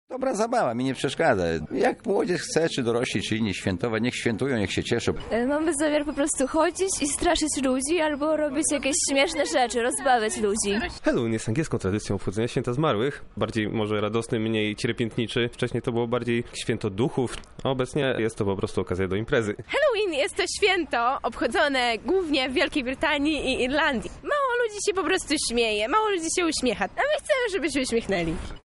Zapytaliśmy mieszkańców Lublina co sądzą o takiej formie świętowania
Sonda